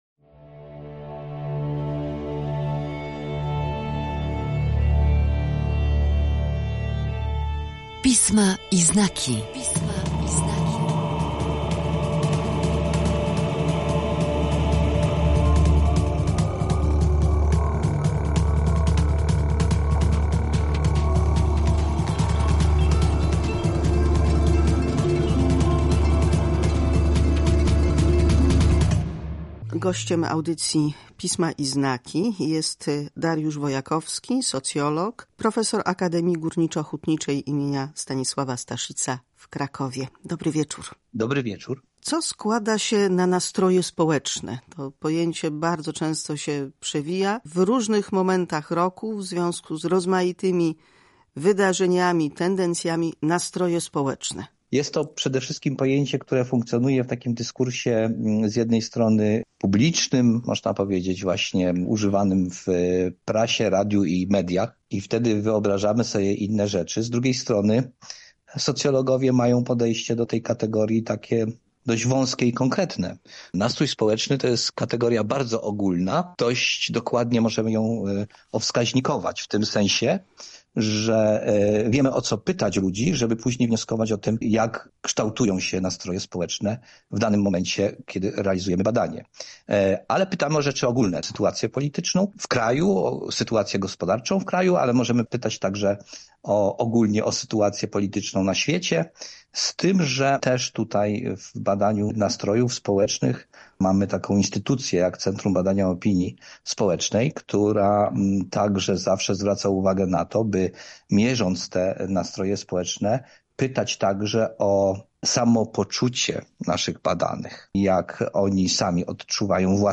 W audycji Pisma i znaki rozmowa z socjologiem